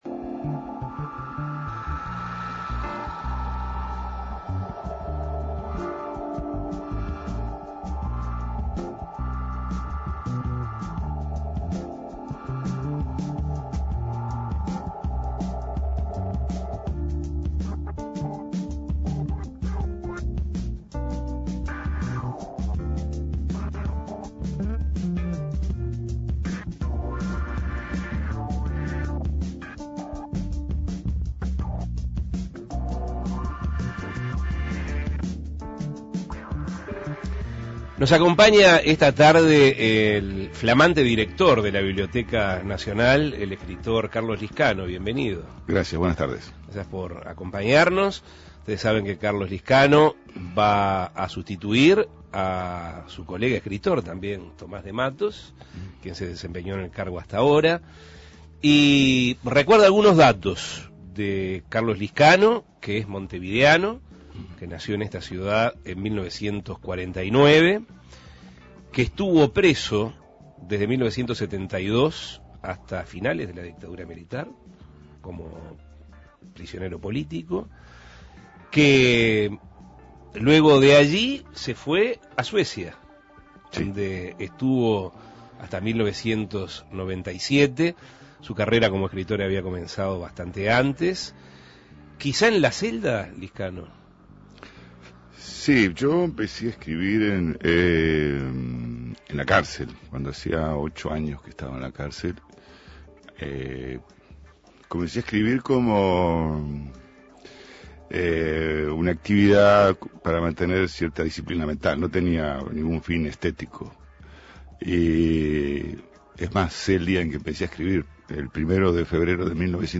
El escritor y ex vice ministro de Educación y Cultura Carlos Liscano fue recientemente nombrado para ese cargo y dialogó sobre qué lo llevó a convertirse en escritor, qué lo inspiró para escribir una de sus más reconocidas obras (que fue hecha cuando se encontraba preso), las razones por las que se exilió en Suecia, y todo el proceso que lo llevó a convertirse en el director de la institución. Escuche la entrevista.